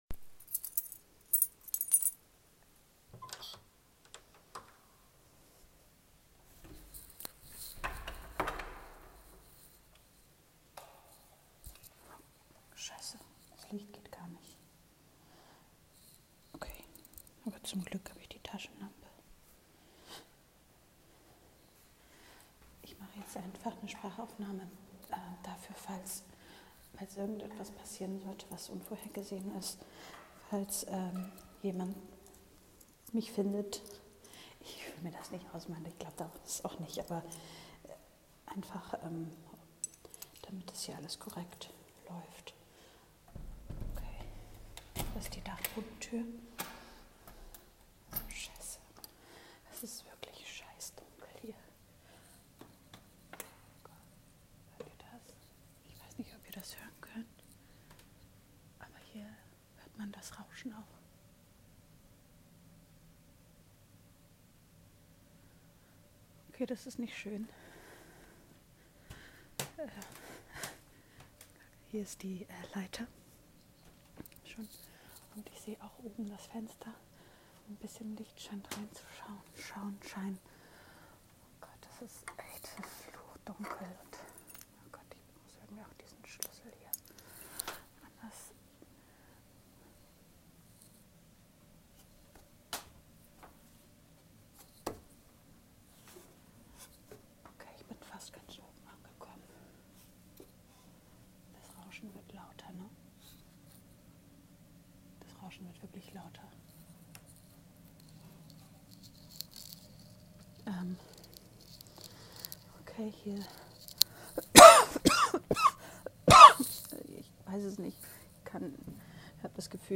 Hier: Sprachaufnahme zur Dokumentation Fevercritters - Dachboden - 06.01.24, 18.15.mp3 Bild von den Hüllen